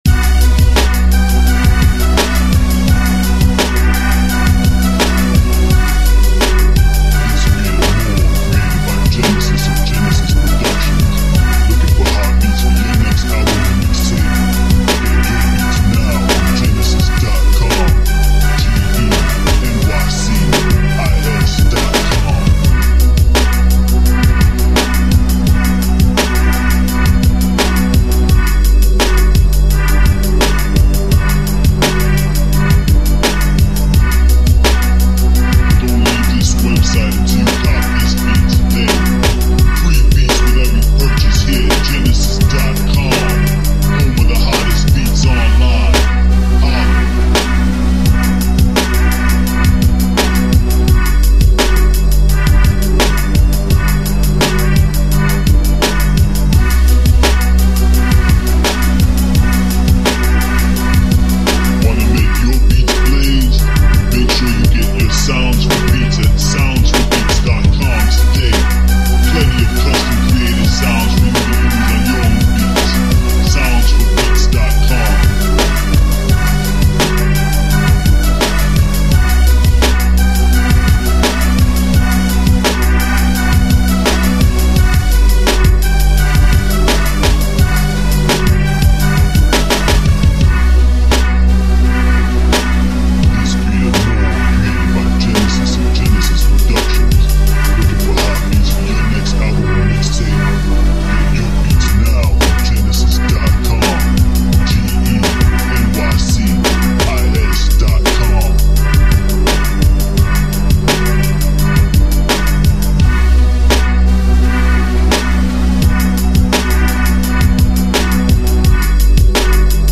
Storytelling Beats